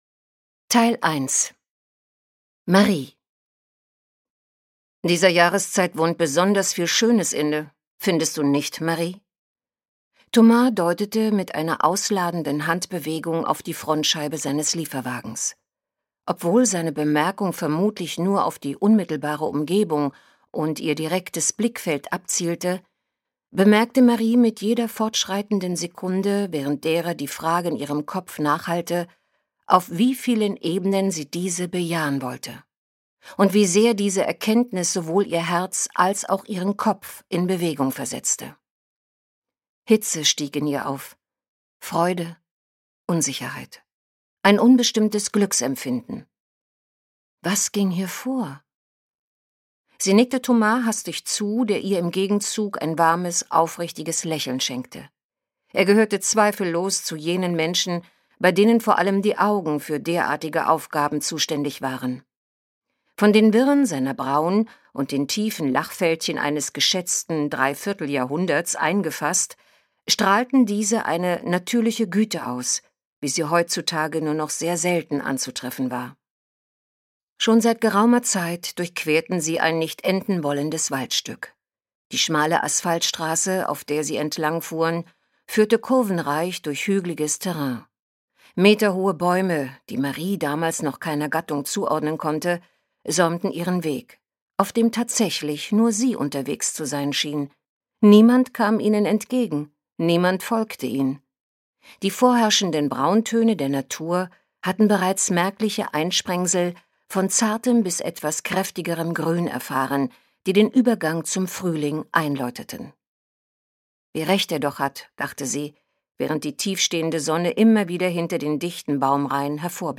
Warmherzig und entschleunigend – ein Hörbuch, das einfach nur guttut
Gekürzt Autorisierte, d.h. von Autor:innen und / oder Verlagen freigegebene, bearbeitete Fassung.